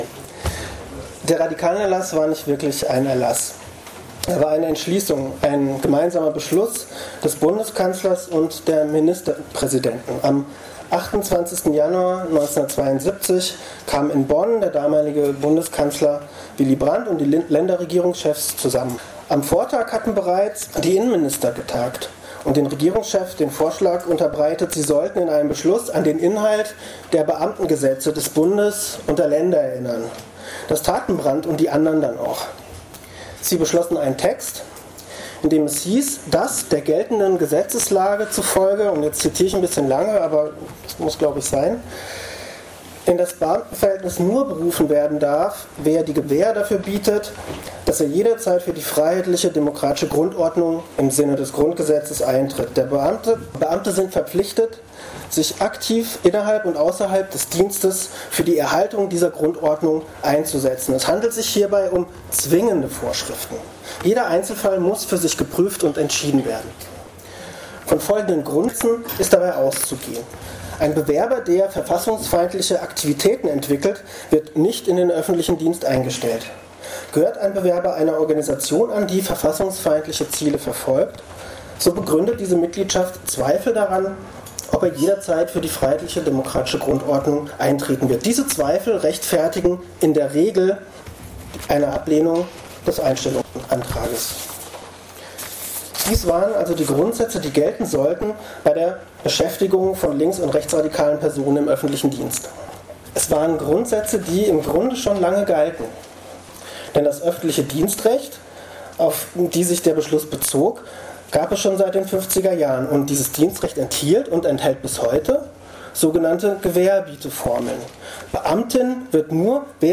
Referat
bei der bundesweiten "Radikalenerlass"-Konferenz der GEW in Kassel am 28.10.2017